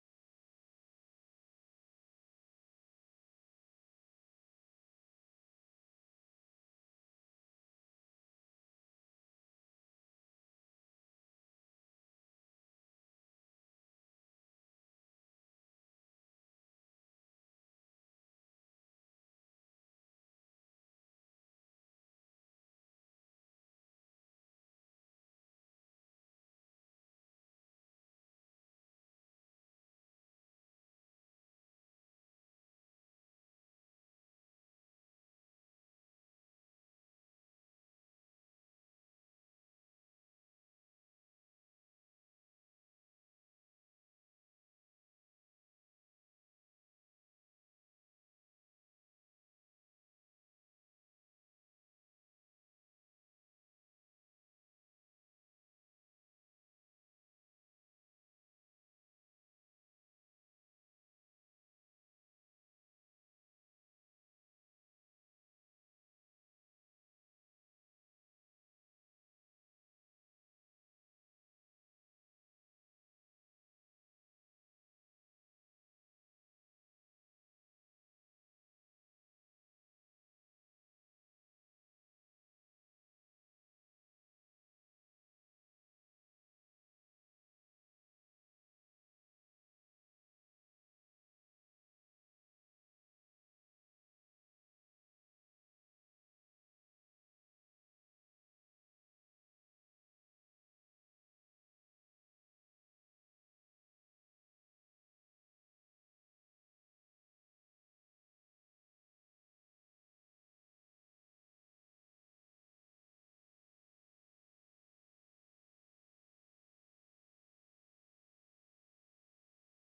September 15 2024 Praise and Worship